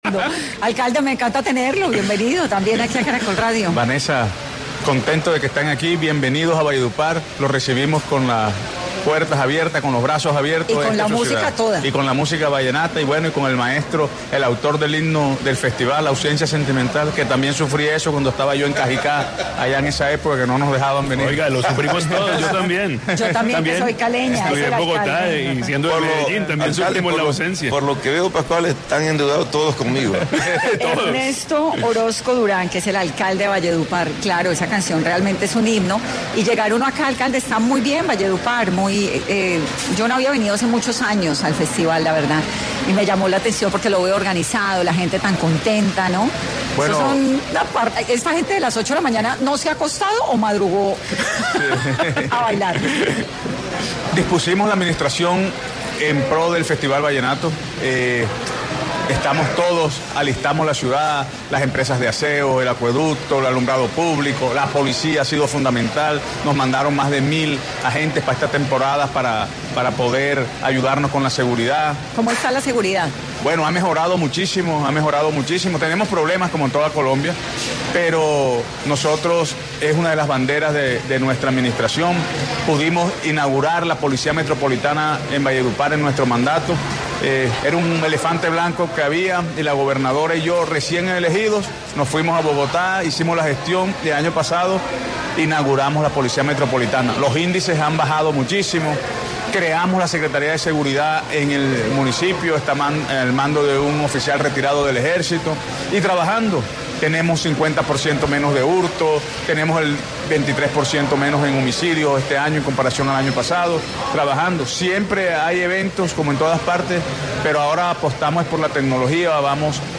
Ernesto Orozco Durán, alcalde de Valledupar, habló en 10 AM sobre la preparación que tuvo la ciudad para la edición 58 del Festival de la Leyenda Vallenata 2025.